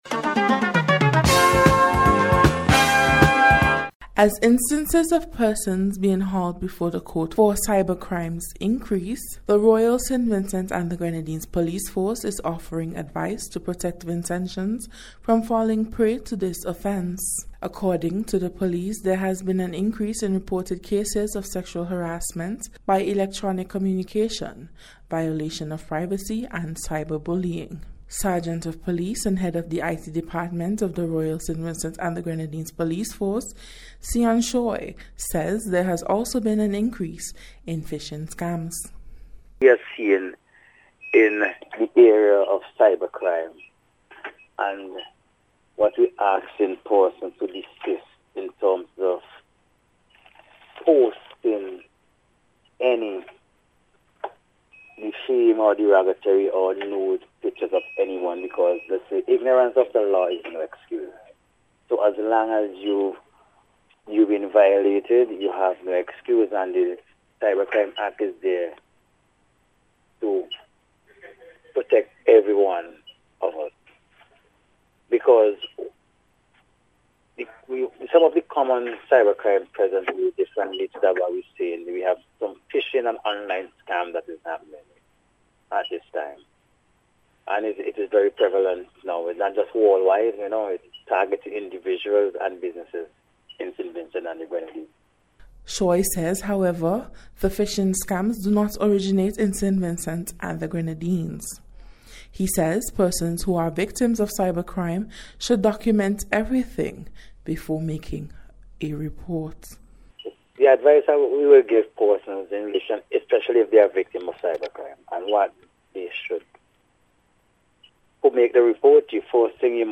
CYBER-CRIME-SPECIAL-REPORT.mp3